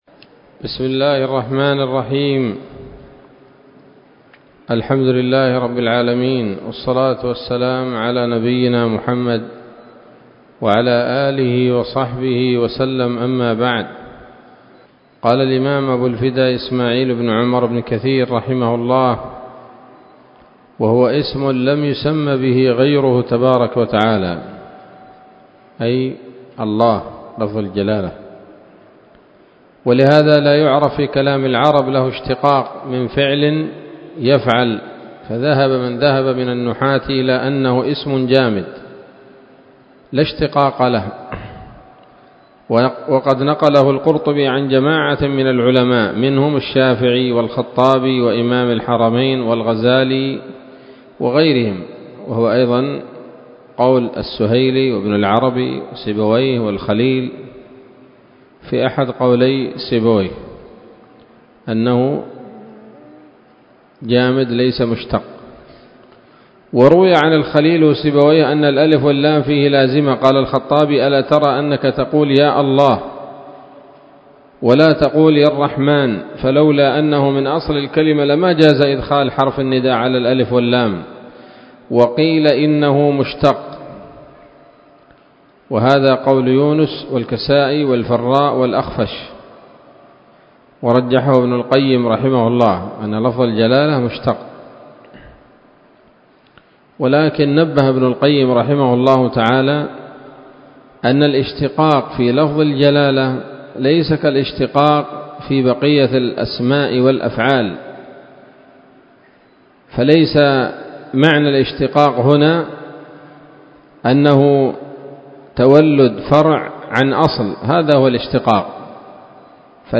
الدرس الثاني عشر من سورة الفاتحة من تفسير ابن كثير رحمه الله تعالى